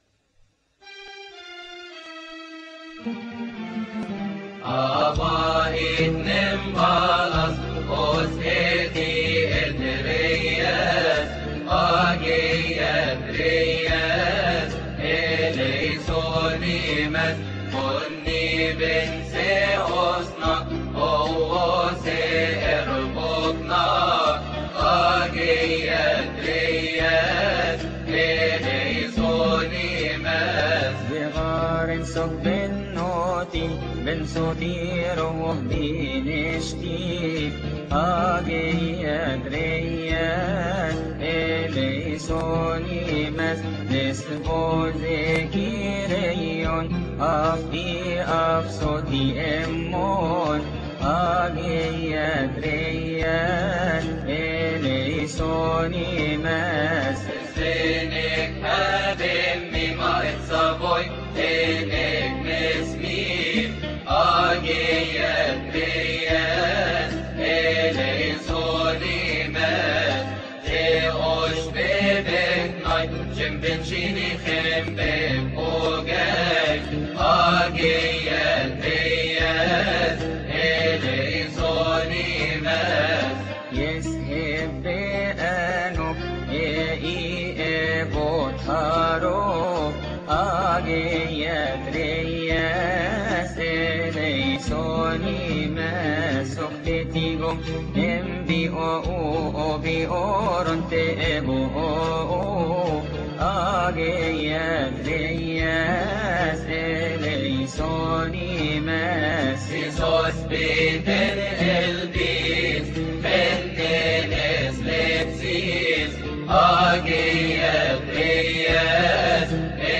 ابصالية آدام رابعة (قلبي و لساني) تقال قبل الهوس الثاني في تسبحة نصف الليل بشهر كيهك لفريق ابو فام الجندي، قبطي.
المصدر: فريق ابو فام الجندي